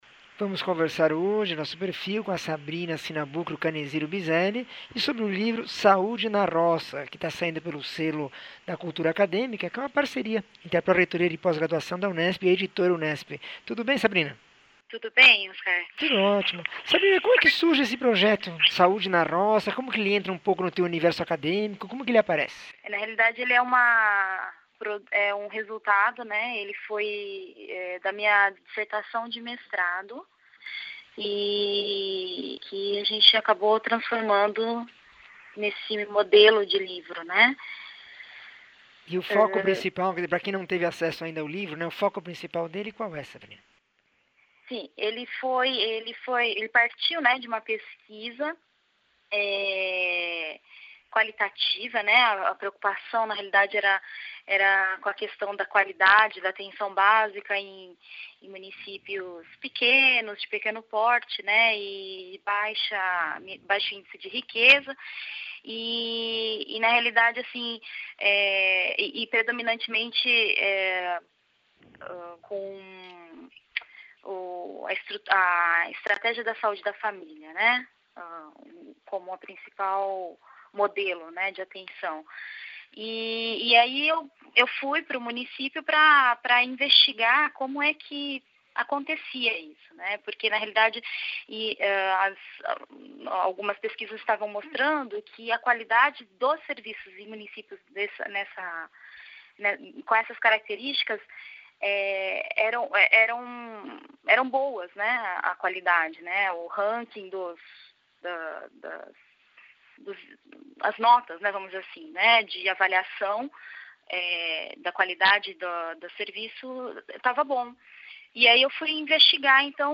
entrevista 1420